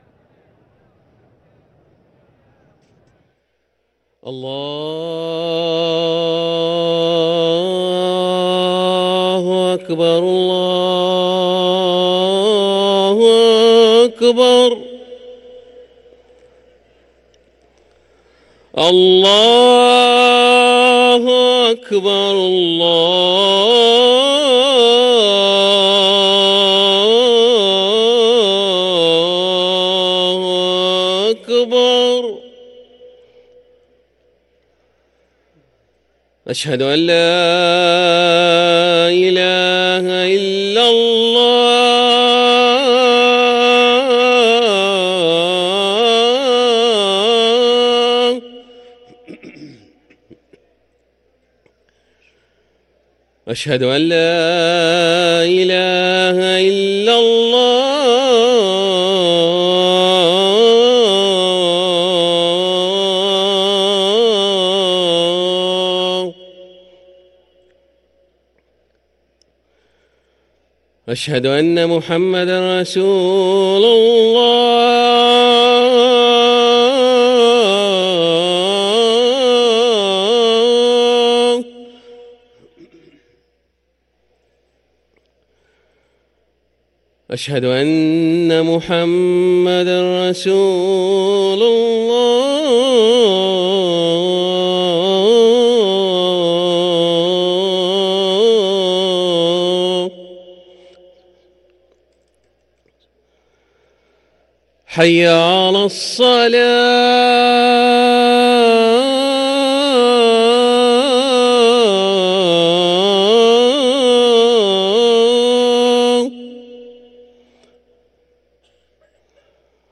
أذان الفجر
ركن الأذان